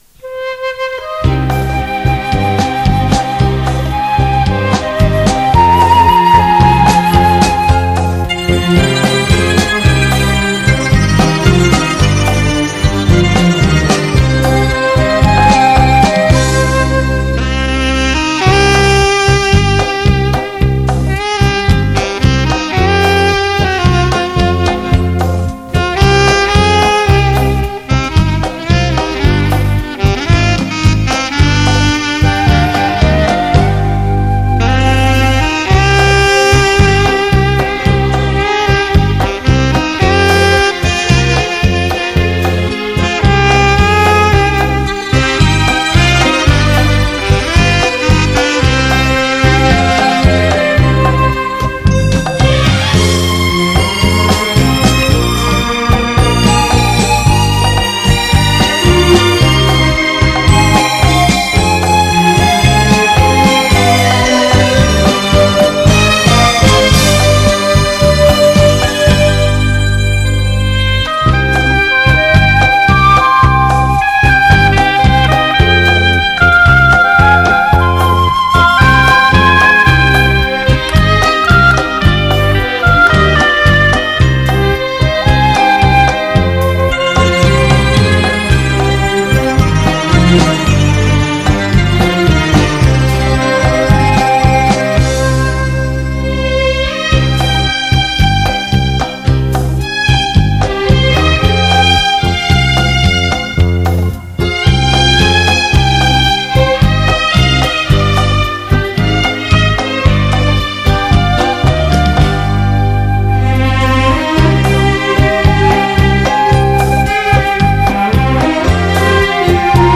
环绕立体声
伦巴
萨克斯
双簧管
长笛